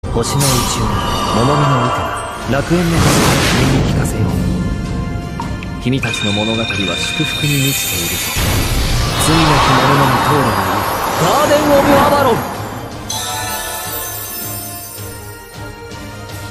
fgo-merlin-revamp-battle-motion-battle-voice-skills-noble-phantasm-youtubemp3free-mp3cut-1.mp3